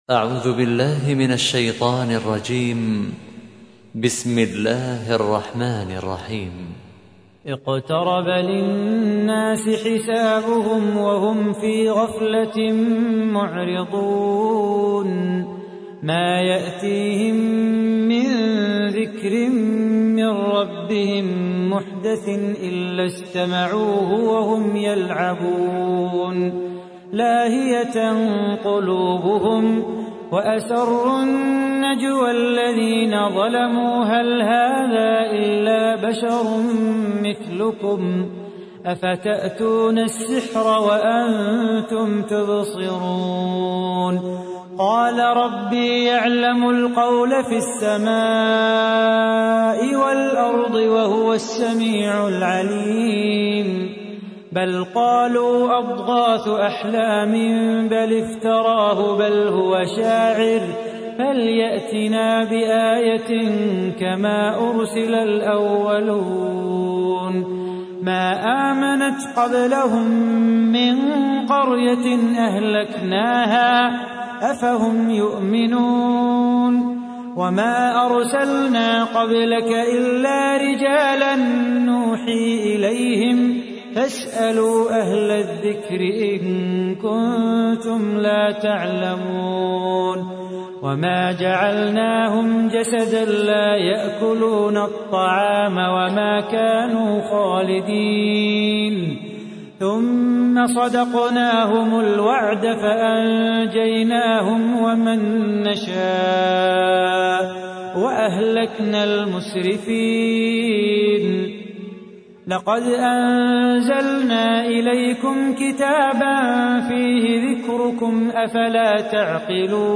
تحميل : 21. سورة الأنبياء / القارئ صلاح بو خاطر / القرآن الكريم / موقع يا حسين